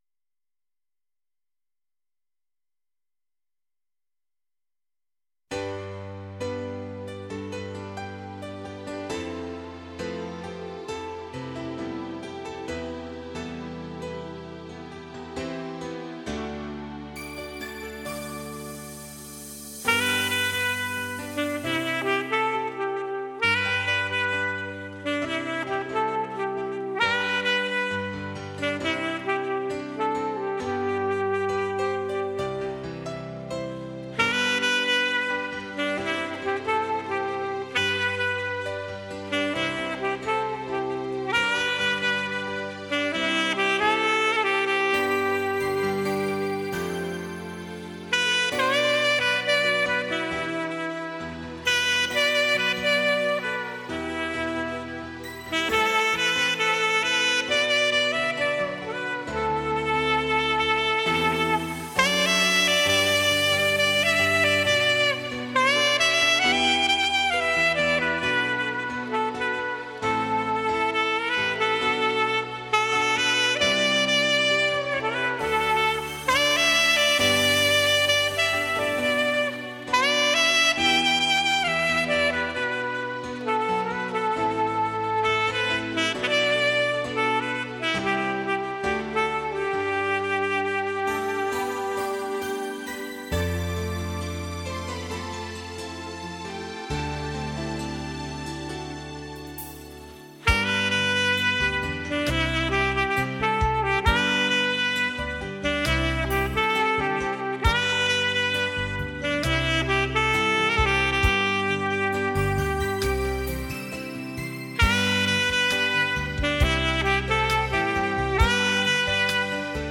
萨克斯演奏